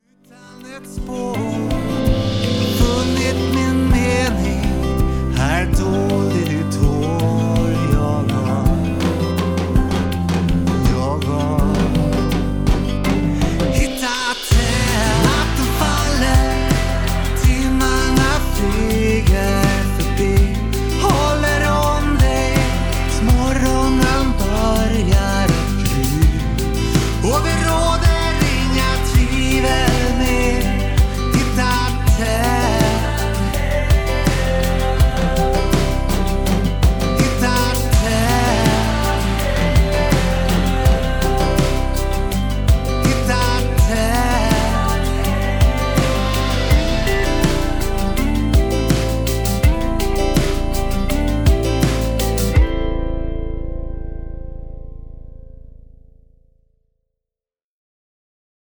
PORTFOLIO – DRUM RECORDINGS
SINGER/SONGWRITER